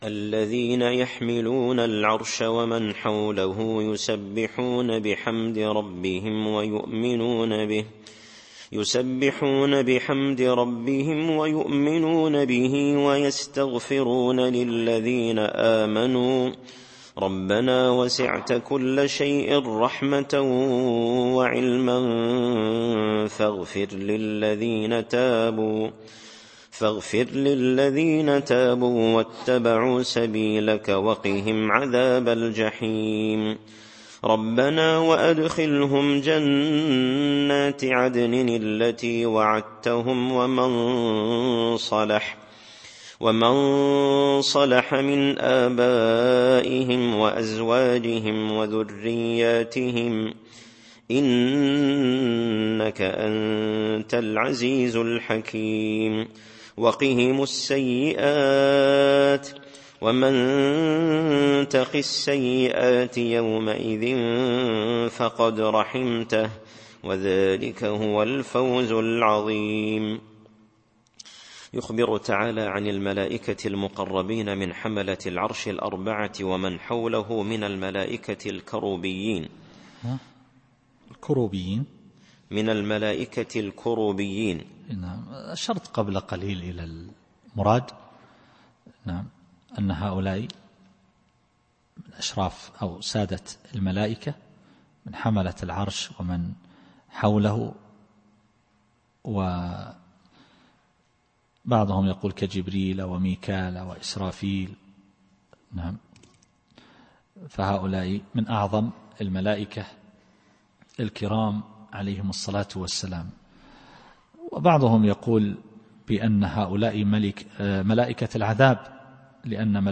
التفسير الصوتي [غافر / 8]